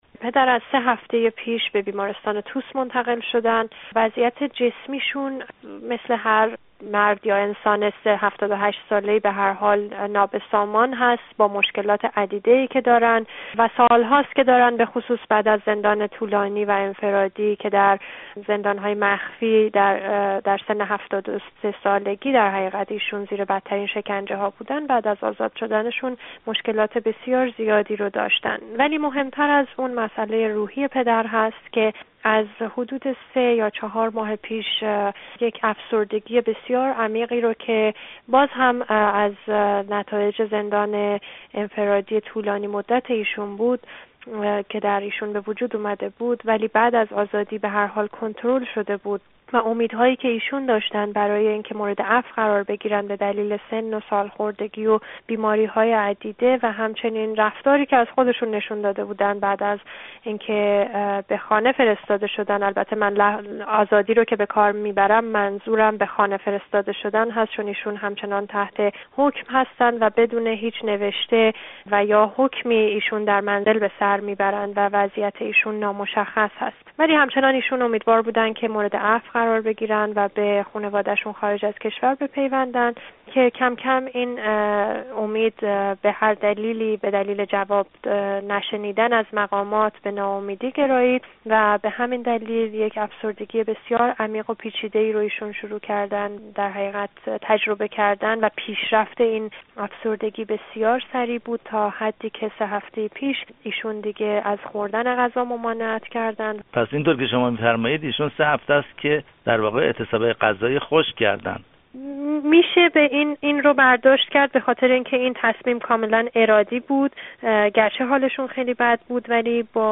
گفت‌گو